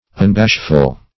Search Result for " unbashful" : The Collaborative International Dictionary of English v.0.48: Unbashful \Un*bash"ful\, a. Not bashful or modest; bold; impudent; shameless.